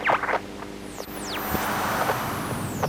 nerfs_psynoise14.ogg